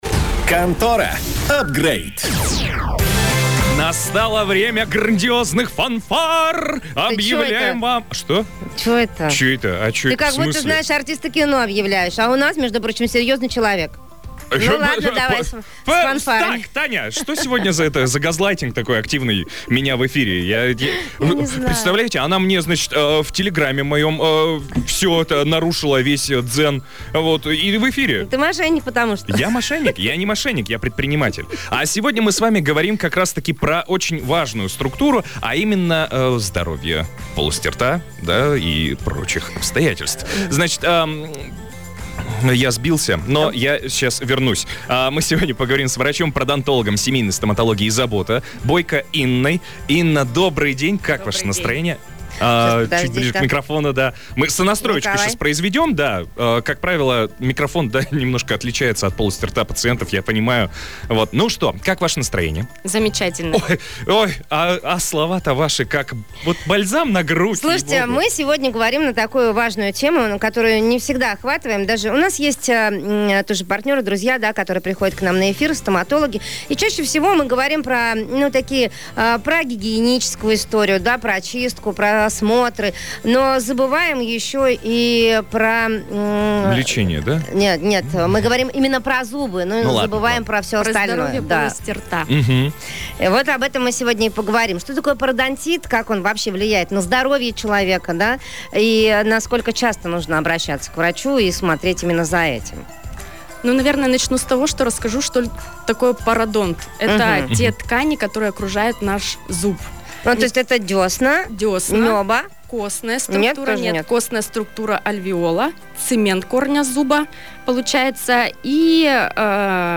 Эфир с пародонтологом